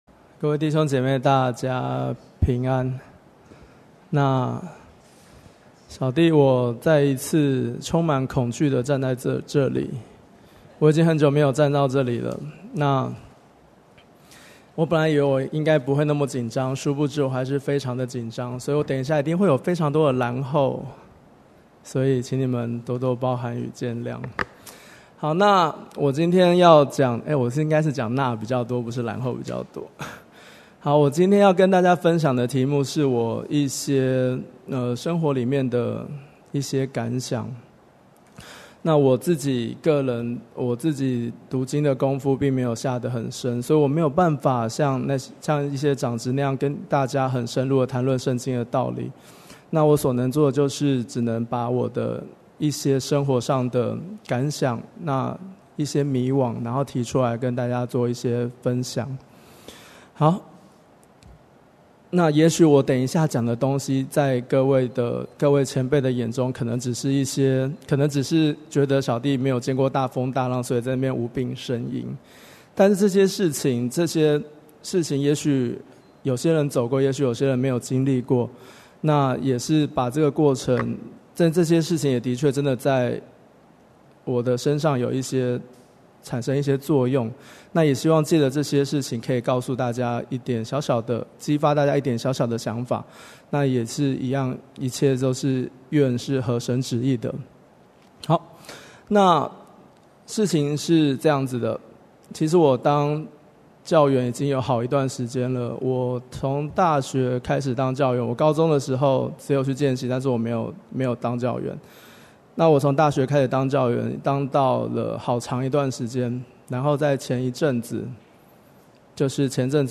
2017年9月份講道錄音已全部上線
(見證會)